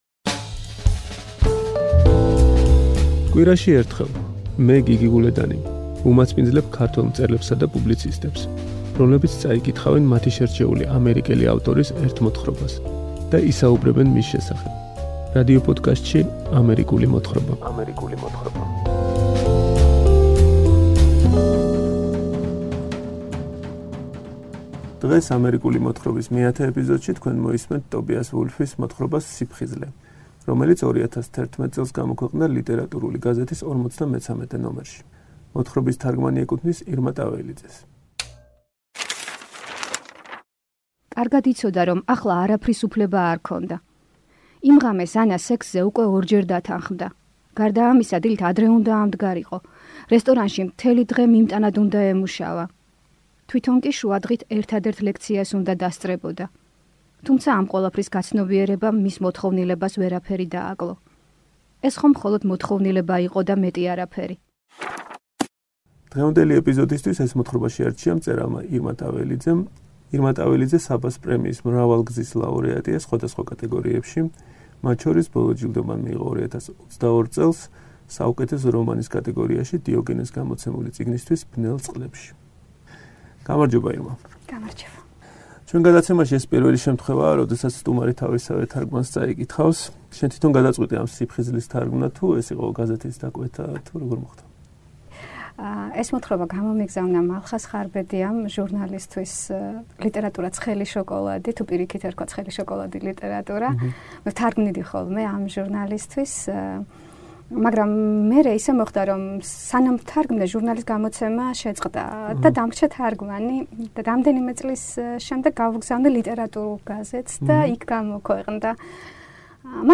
კითხულობს ტობიას ვულფის “სიფხიზლეს”